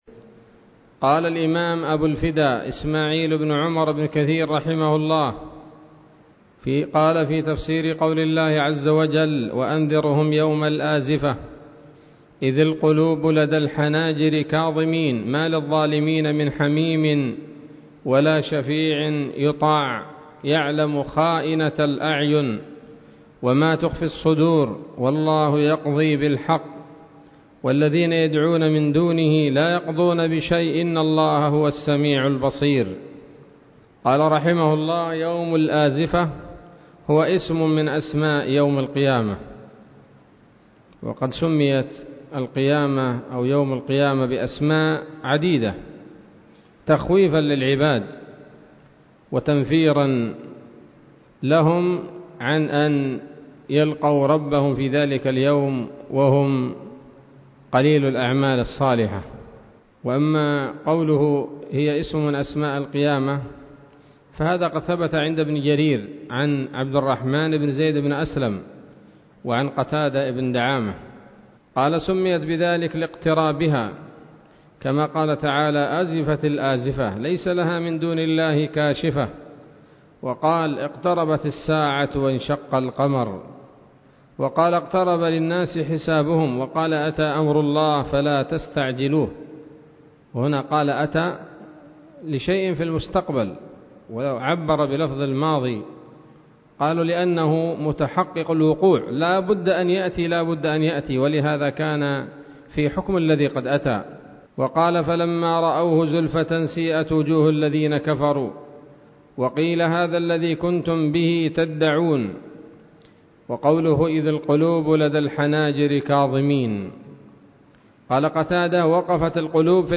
الدرس السادس من سورة غافر من تفسير ابن كثير رحمه الله تعالى